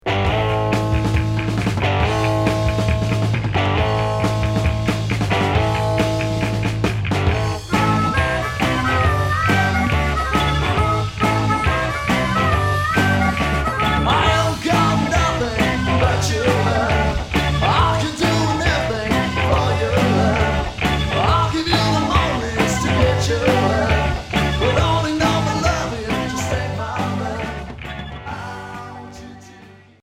Garage